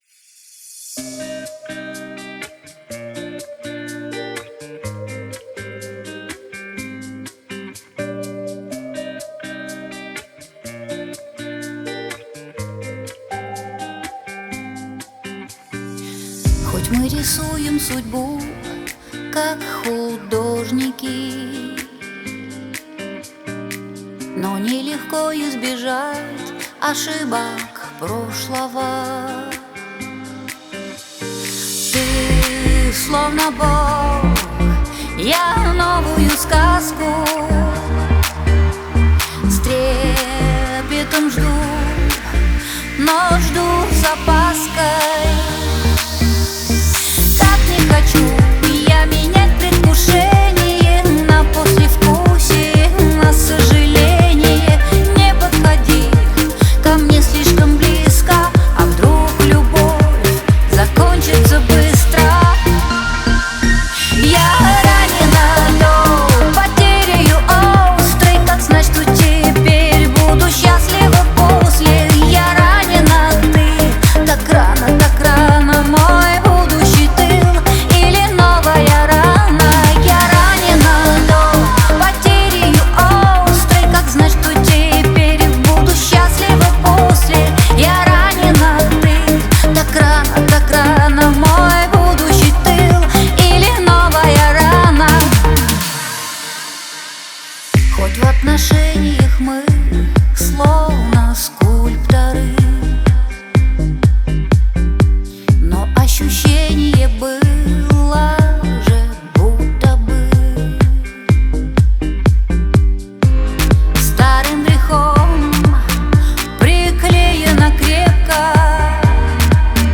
чувственное вокальное мастерство